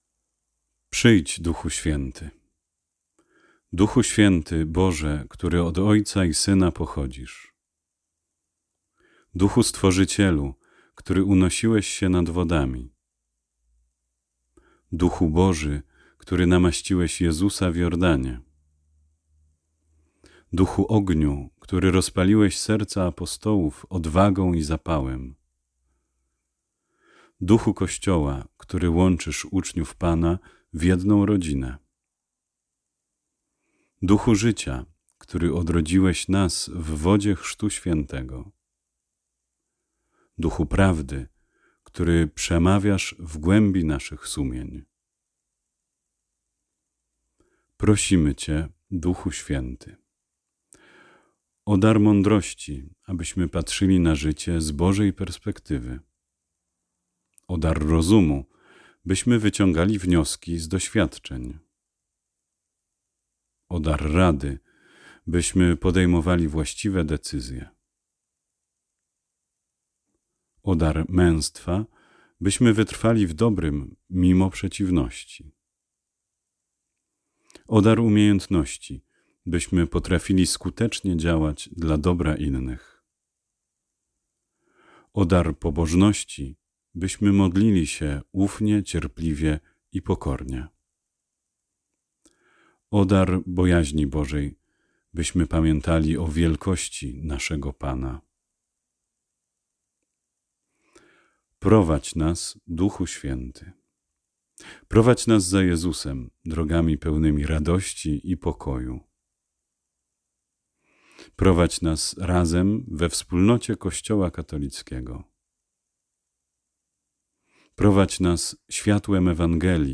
Modlitwa
Modlitwa, którą proponuję Ci poniżej, składa się z trzech części po 7 wezwań.
Litania-do-Ducha-Swietego.mp3